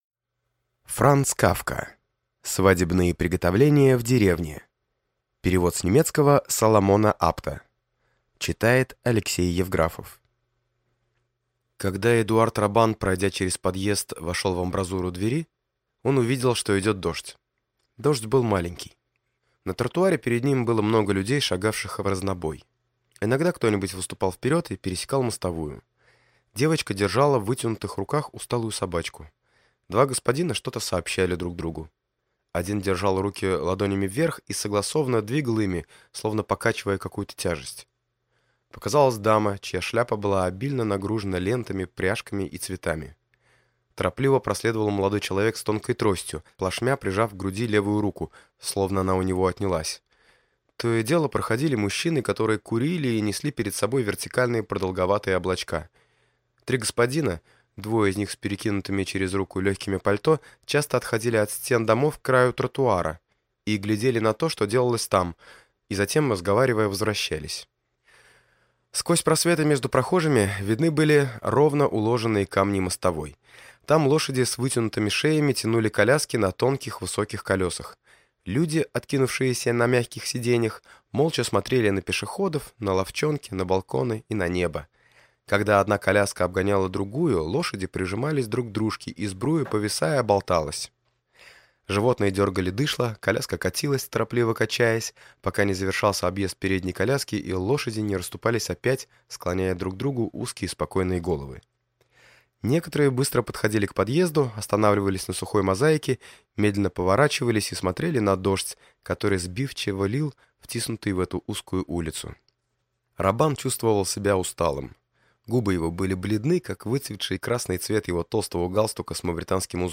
Аудиокнига Свадебные приготовления в деревне | Библиотека аудиокниг